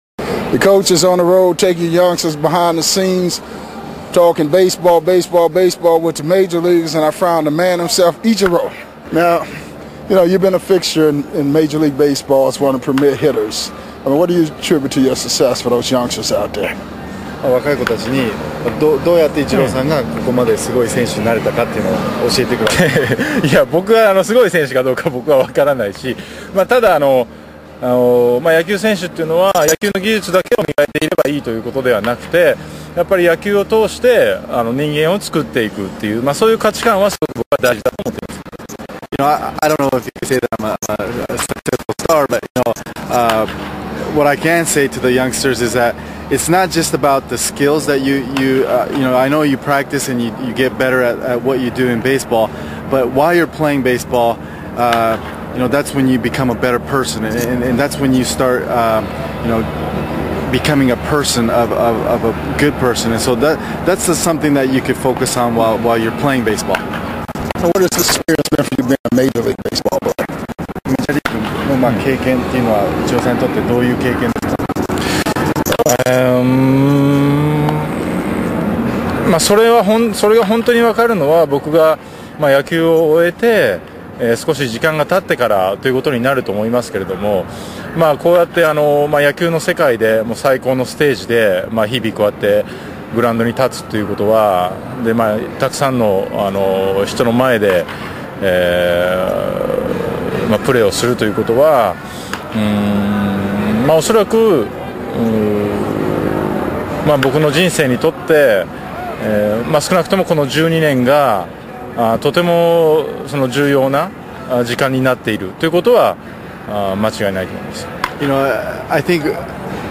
full uncut and unedited MLB interviews with past and present players